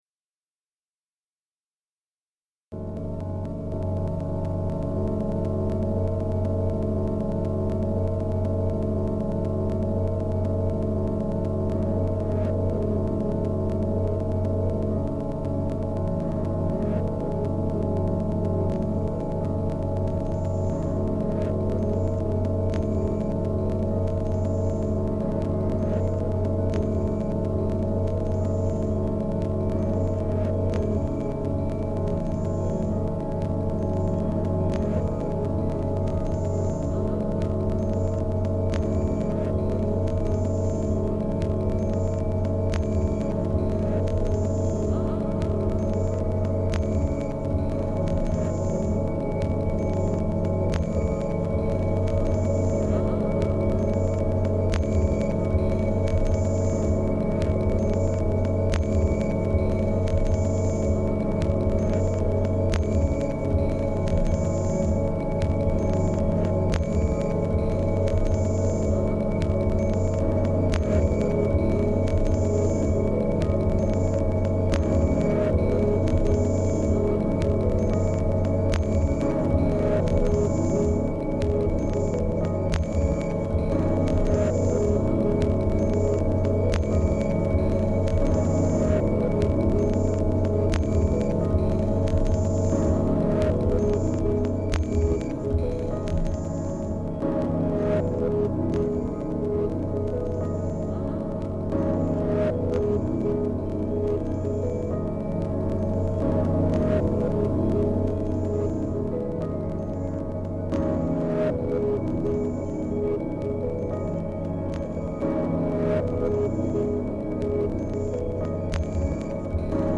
abstracto pero rico en texturas.